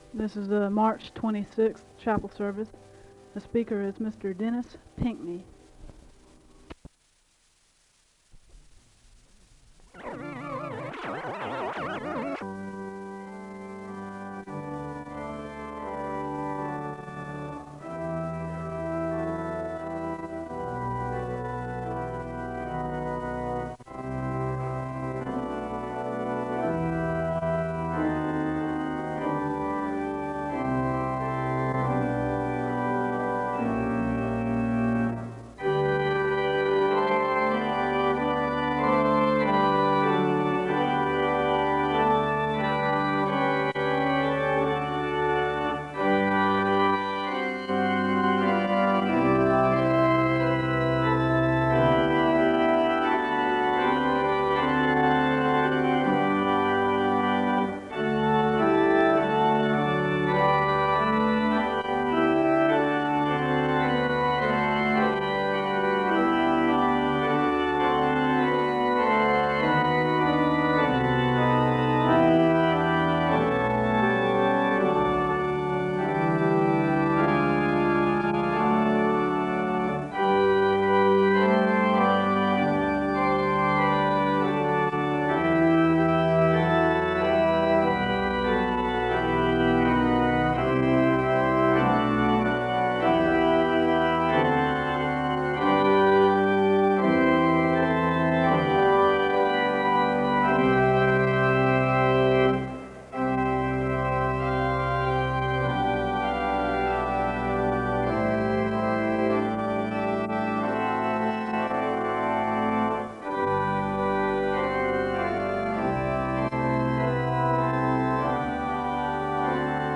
The service begins with organ music, a Scripture reading from Psalms, and a moment of prayer (0:00-4:47).
There is a Scripture reading from Matthew 16:21-26 (4:47-7:40).
The service ends in a moment of prayer (30:49-31:36).